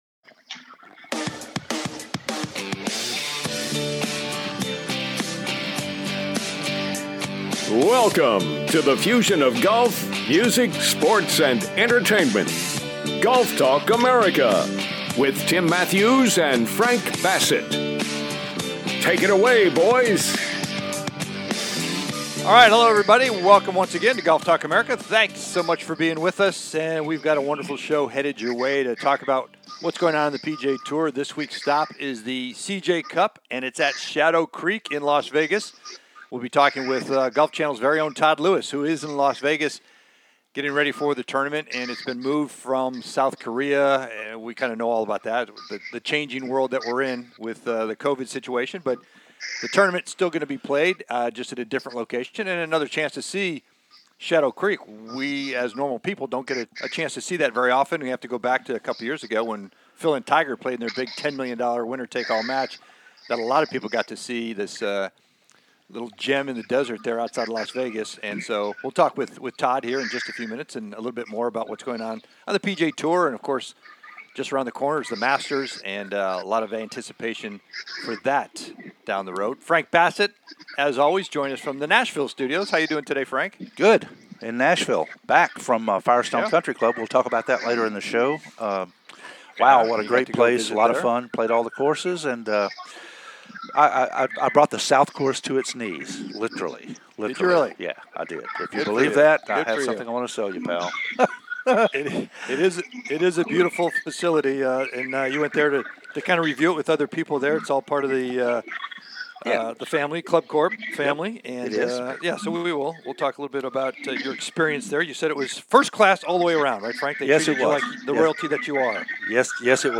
"LIVE" On His Morning Walk Down The Vegas Strip During This Weeks CJ Cup From Shadow Creek